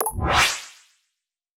win_sound.wav